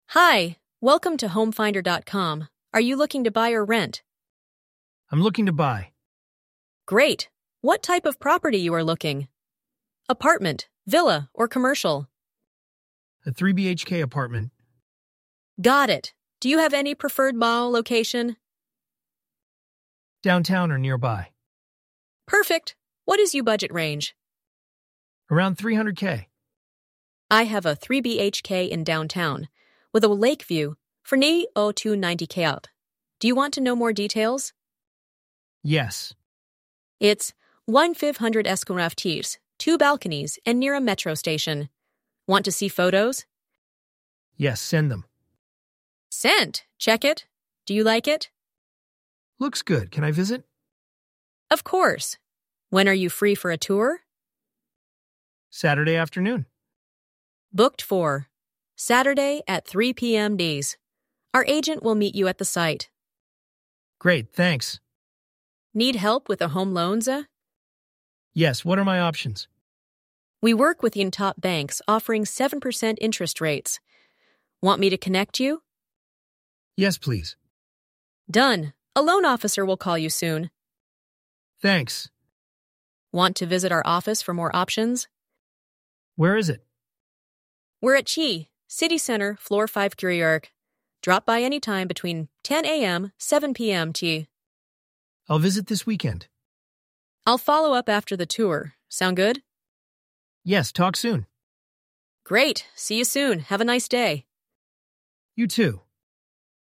Experience AI-powered voice agents handling real-time calls.
🏠 Real Estate AI Voice Agent